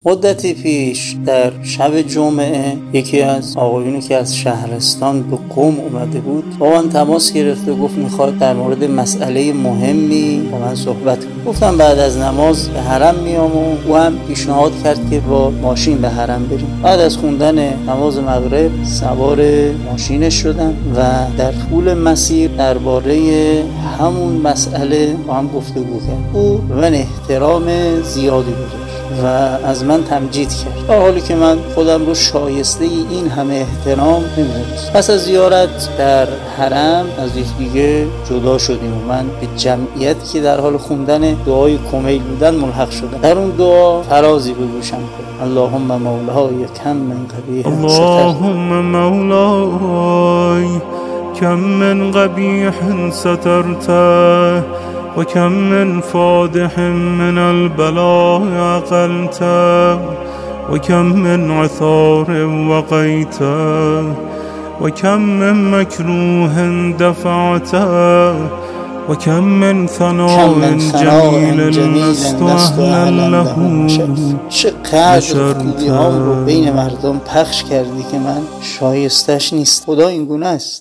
دعای کمیل
شب جمعه